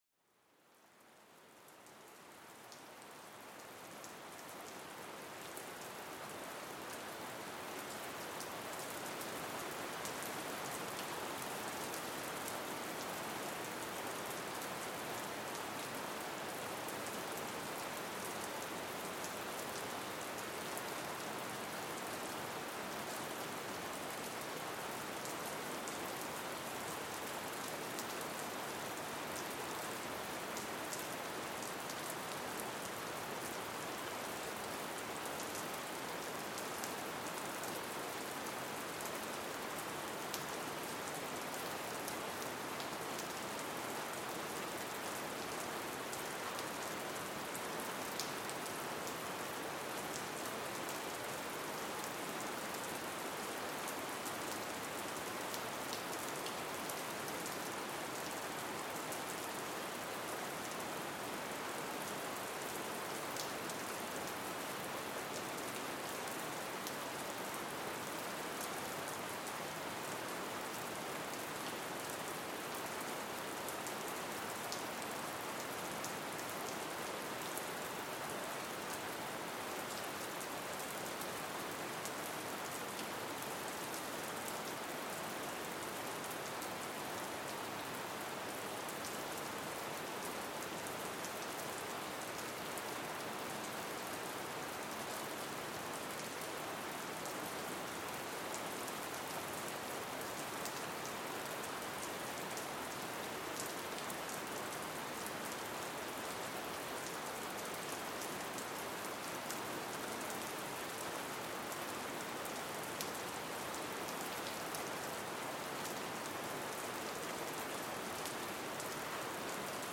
Lluvia relajante: una sinfonía para calmar la mente